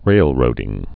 (rālrōdĭng)